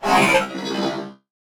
CosmicRageSounds / ogg / general / combat / ENEMY / droid / hurt2.ogg
hurt2.ogg